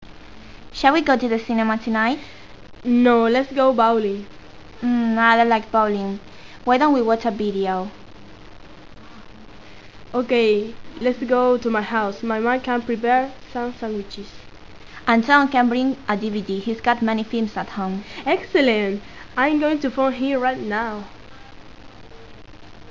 Everyday conversations
Dos chicas de pié en jardín mantienen una conversación